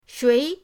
shui2.mp3